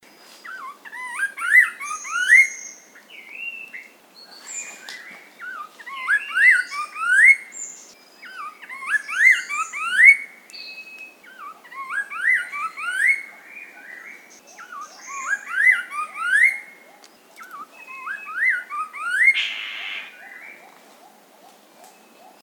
Suara Burung Poksay